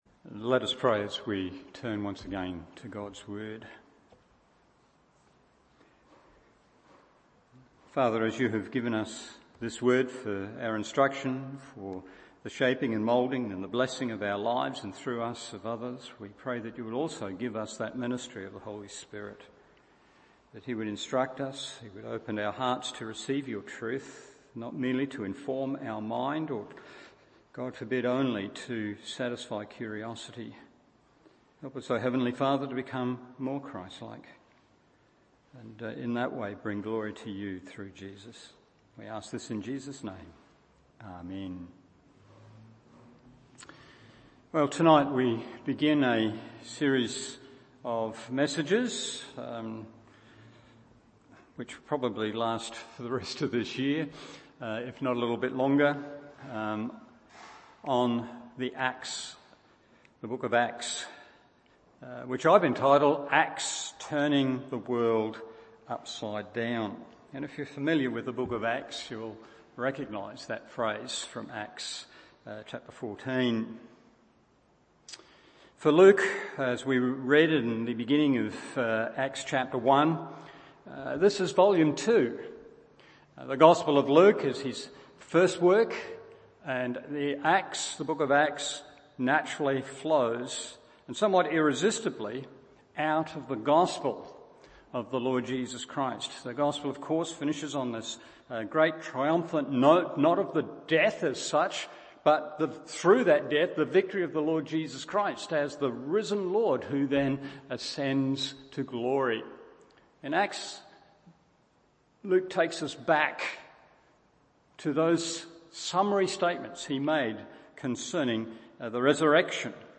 Evening Service Acts 1:1-11 1. The Person to Express 2. The Power to Enable 3. The Purpose to Embrace…